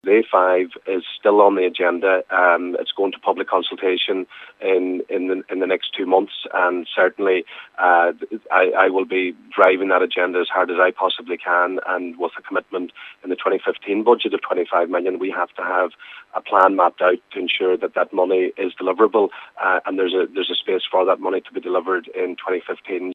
And Donegal Deputy Joe McHugh says money from next years budget will go towards the A5: